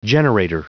Prononciation du mot generator en anglais (fichier audio)
Prononciation du mot : generator